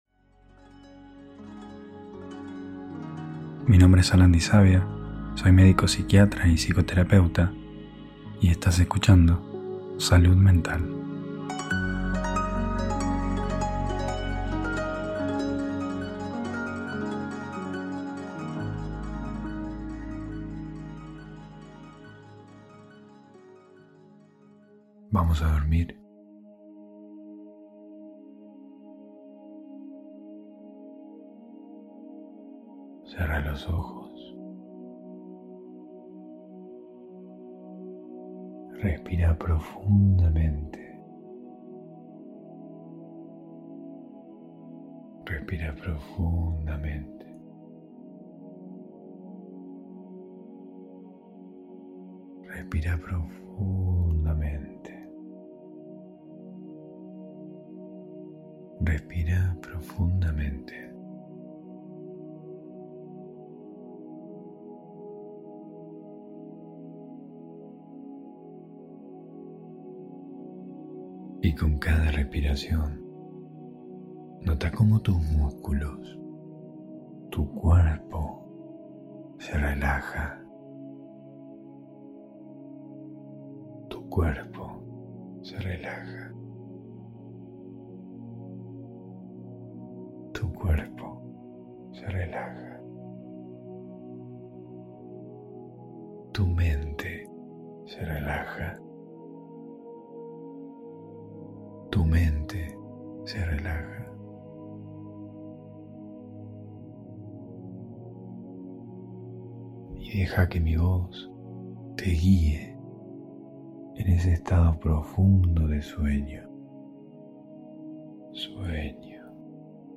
Hipnosis guiada para dormir.